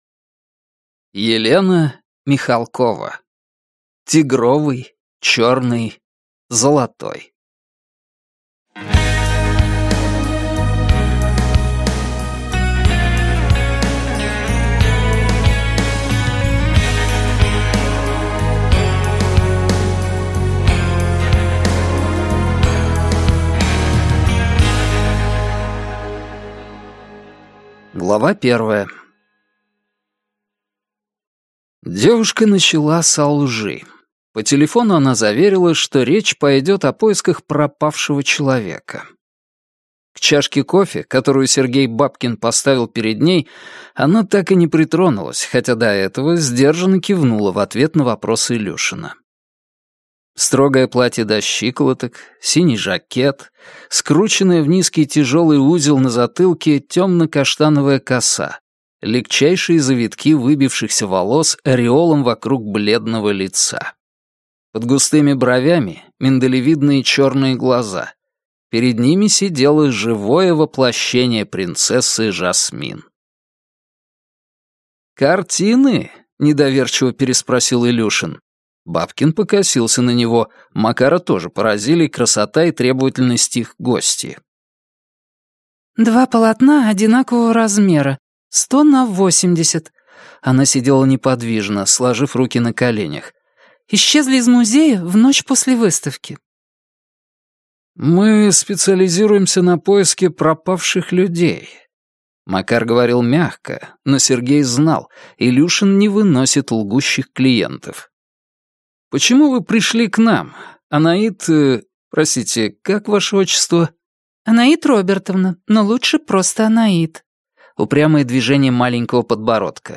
Аудиокнига Тигровый, черный, золотой | Библиотека аудиокниг